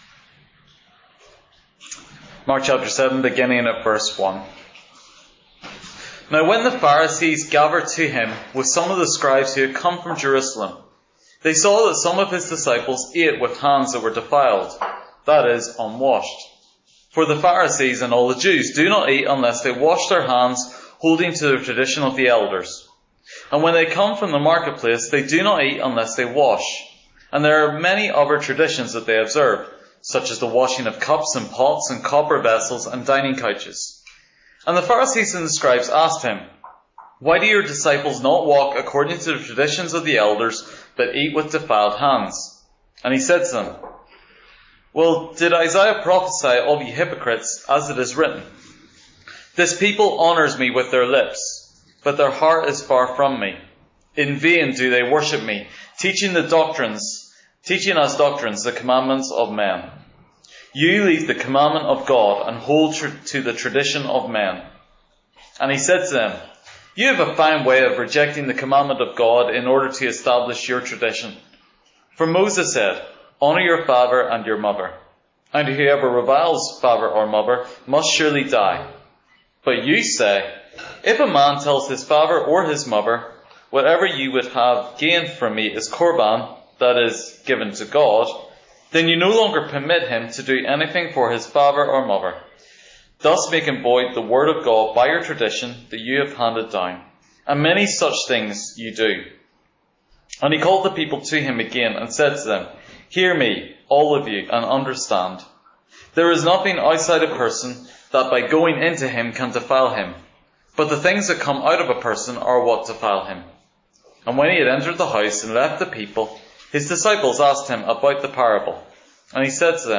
Guest Interviews: Can self help really save?